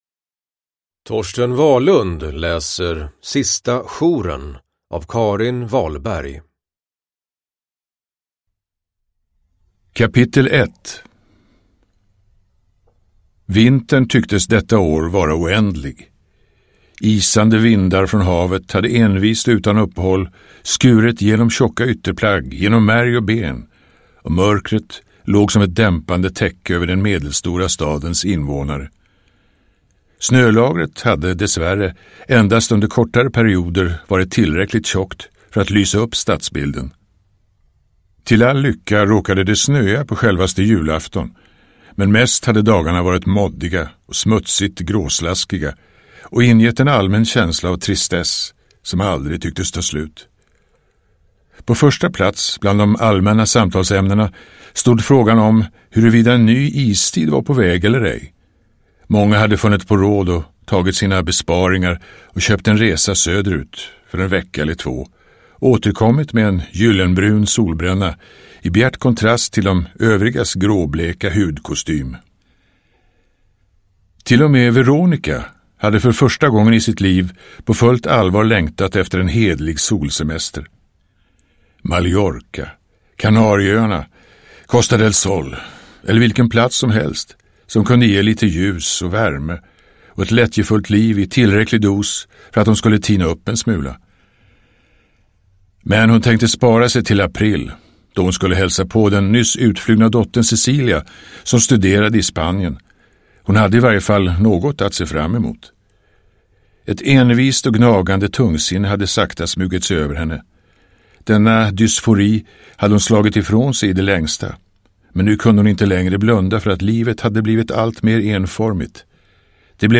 Uppläsare: Torsten Wahlund
Ljudbok